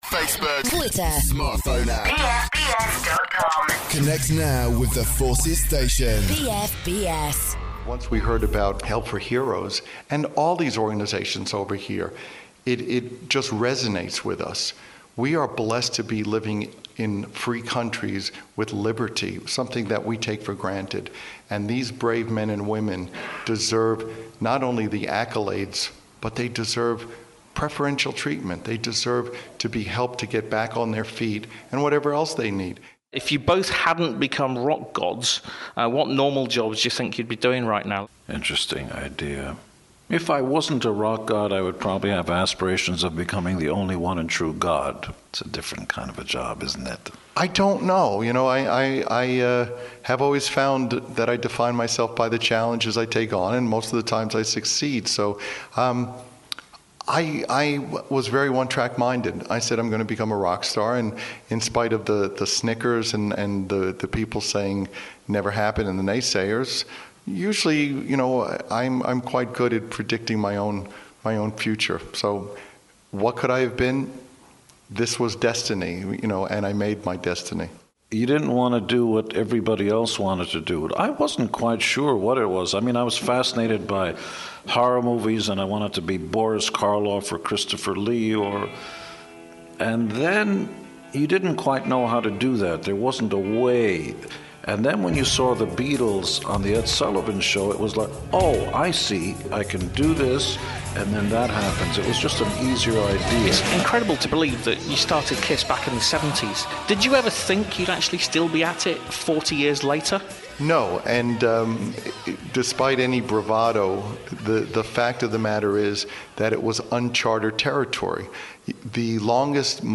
KISS talk to BFBS Rocks part 2